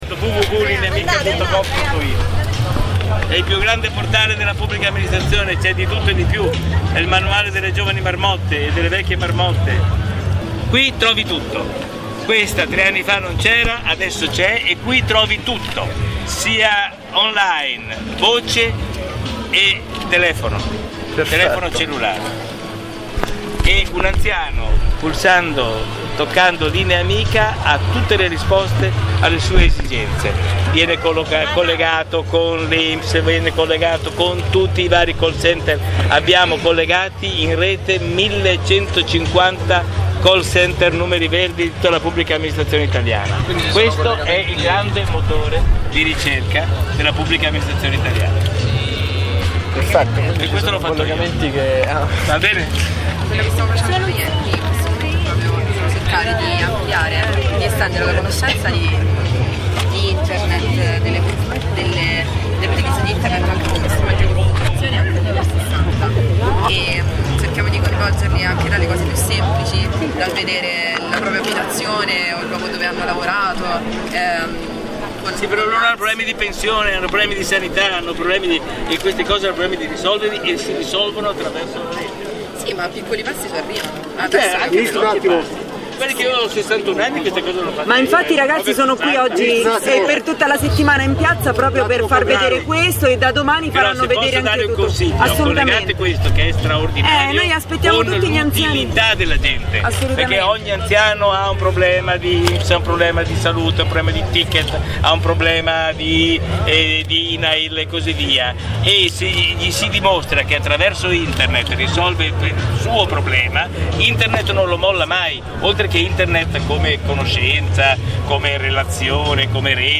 Oggi a Viterbo, al Festival di cultura digitale Medioera, è arrivato anche il ministro Renato Brunetta.